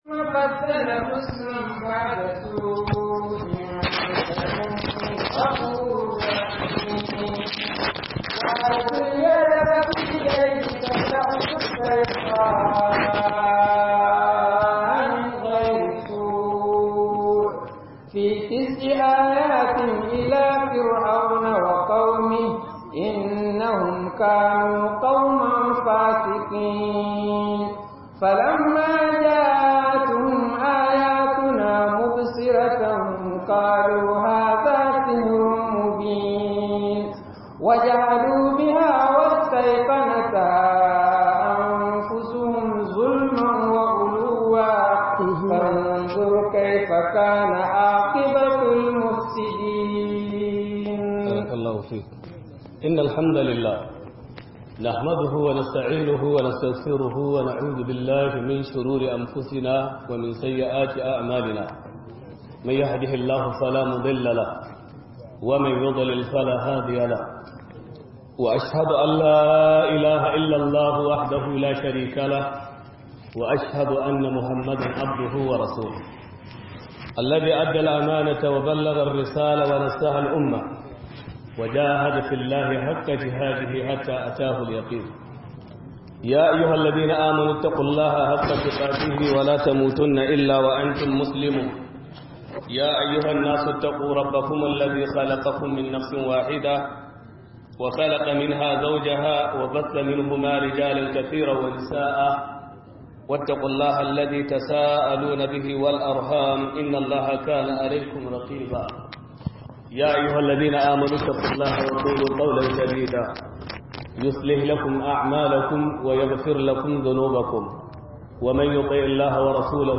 الأذان وأخطاء المؤذنين - MUHADARA